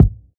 PreKik.wav